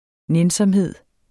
Udtale [ ˈnεnsʌmˌheðˀ ]